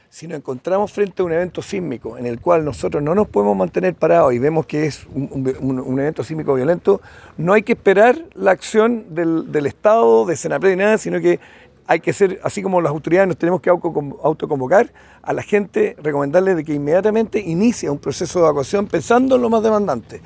El contraalmirante y jefe de la Segunda Zona Naval, Arturo Oxley, agregó debido a los años transcurridos tras el terremoto y tsunami de 2010, la población debe conocer los cuales por los cuales evacuar.